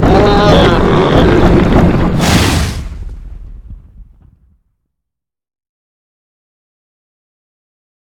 bearcharge.ogg